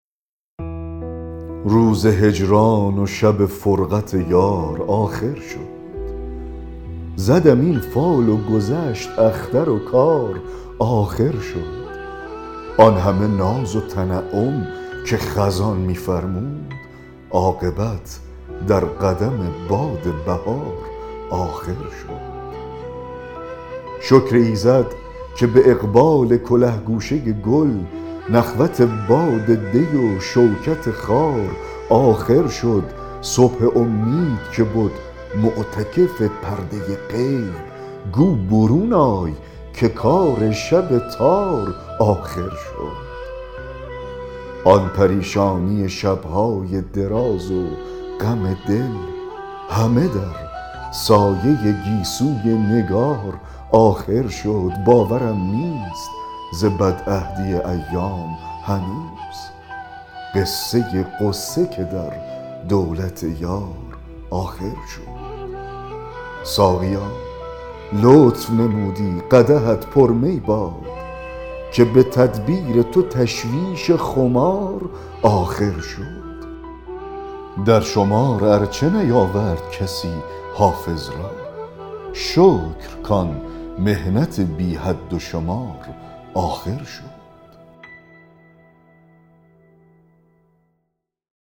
دکلمه غزل 166 حافظ
دکلمه-غزل-166-حافظ-روز-هجران-و-شب-فرقت-یار-آخر-شد-.mp3